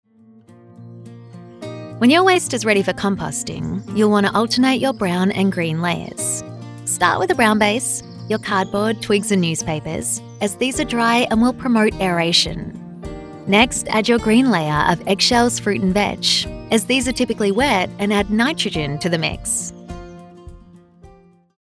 E-learning
Neumann U87
Soprano